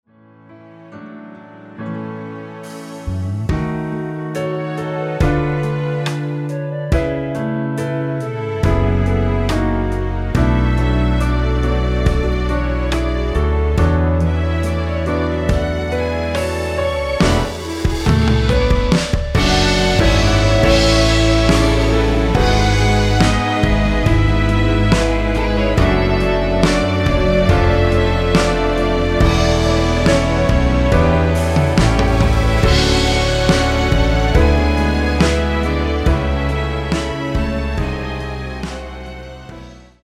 원키에서(-1)내린 멜로디 포함된(1절앞 + 후렴)으로 진행되는 MR입니다.
앞부분30초, 뒷부분30초씩 편집해서 올려 드리고 있습니다.